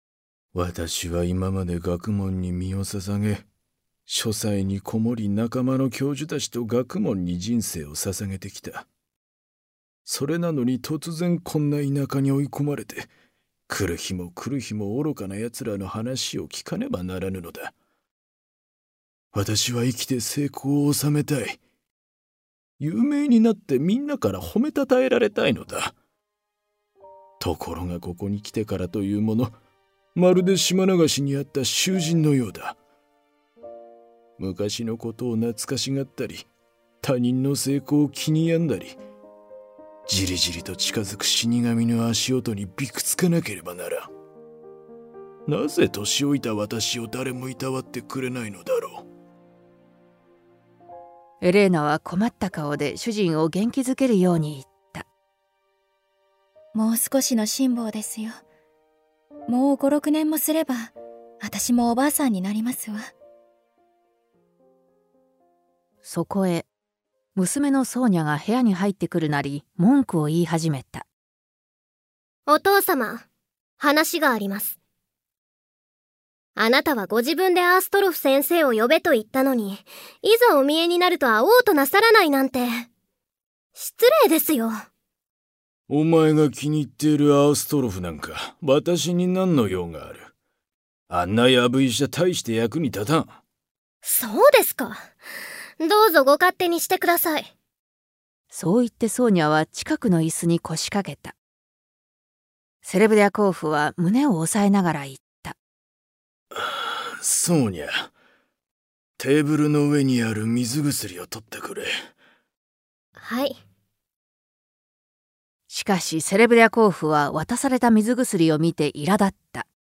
[オーディオブック] ワーニャ伯父さん（こどものための聴く名作 38）